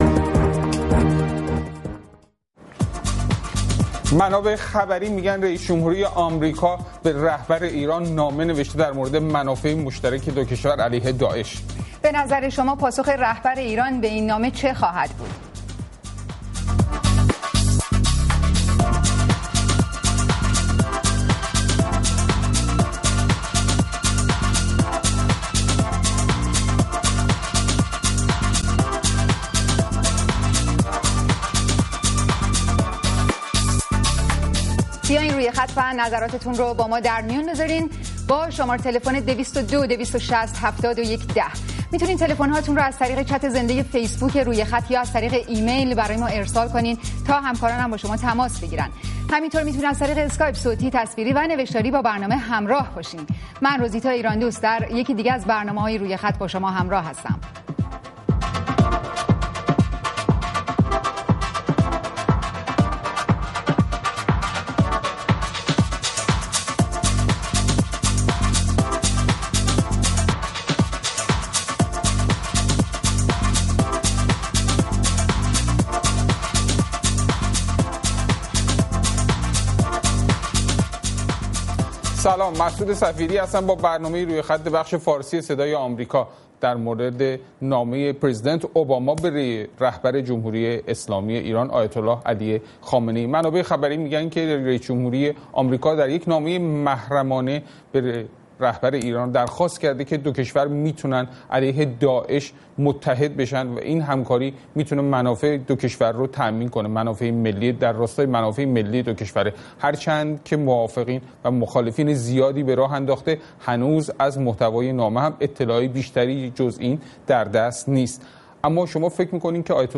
روی خط برنامه ای است برای شنیدن نظرات شما. با همفکری شما هر شب یک موضوع انتخاب می کنیم و شما می توانید از طریق تلفن، اسکایپ، فیس بوک یا ایمیل، به صورت زنده در بحث ما شرکت کنید.